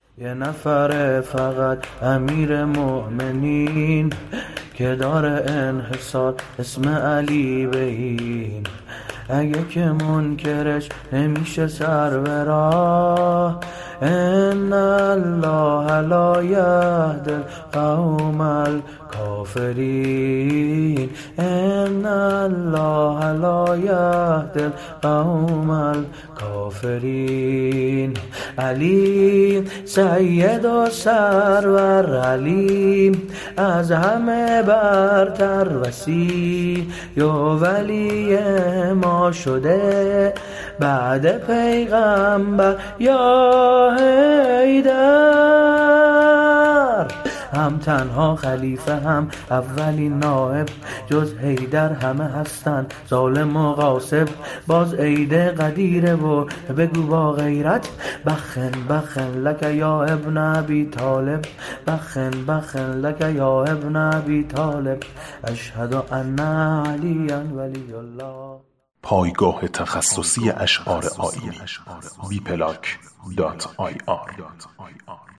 علی المرتضی عید غدیر شور منحصرِ علیطه تحقیقی